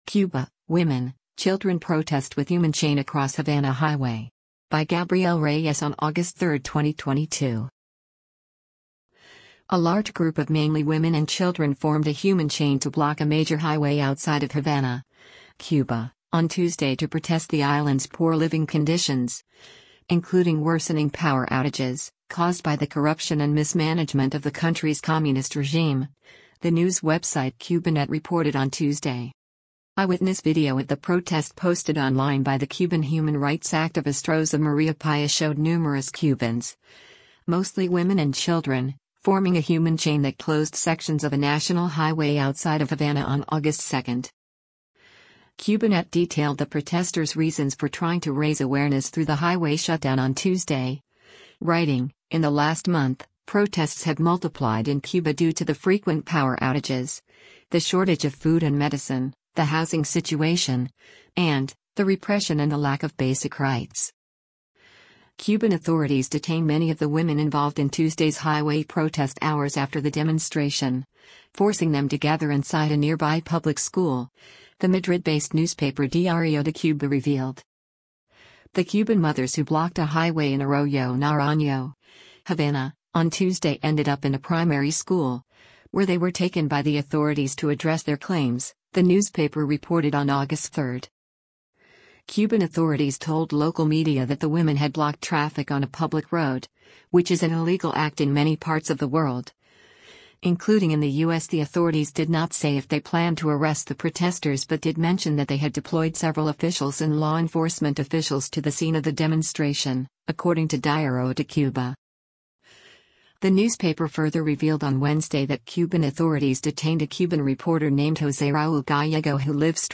Cuban mother and children block major highway into Havana, Cuba, August 2, 2022.